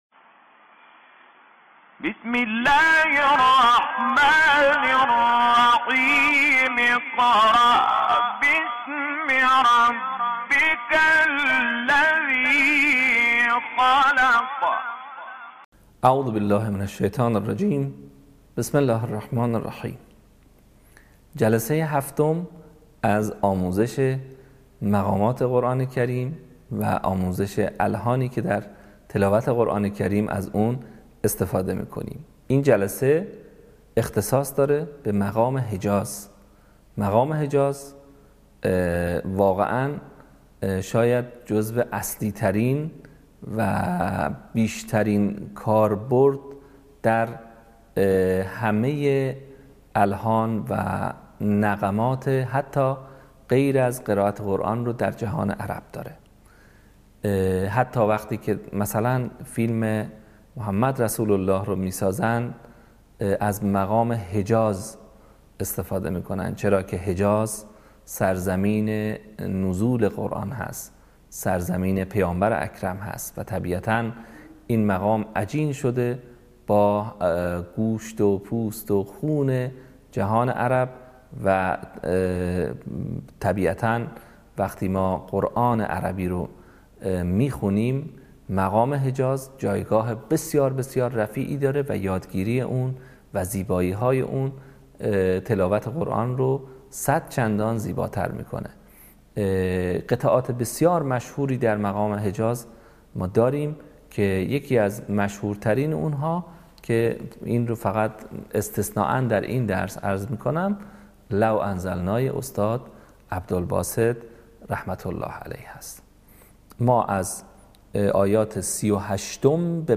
صوت | آموزش مقام حجاز